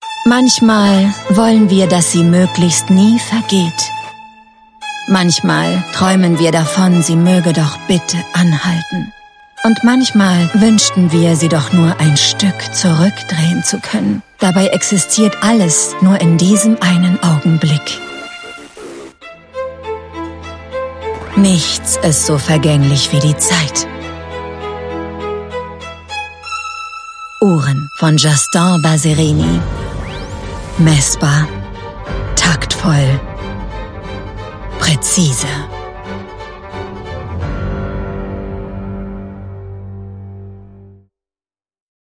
TVC Welt am Sonntag